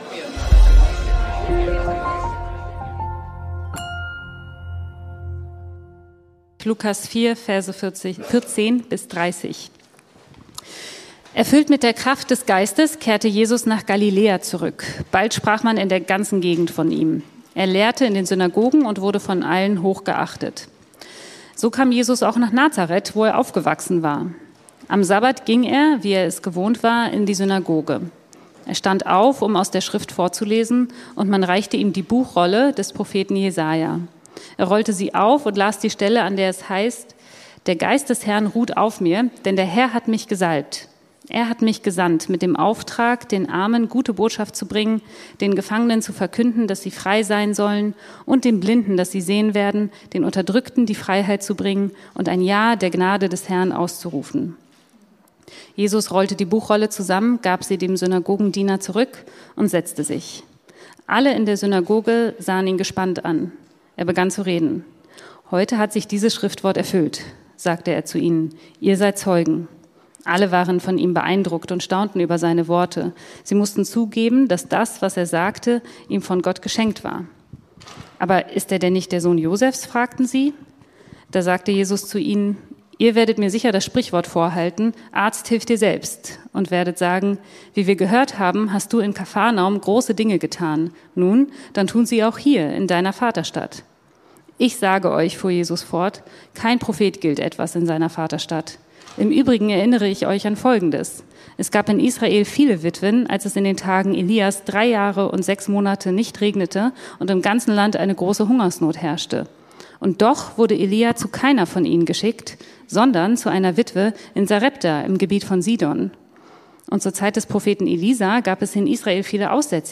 Berufen zu einer Mission auf dem Weg zur WIRKLICH ~ Predigten der LUKAS GEMEINDE Podcast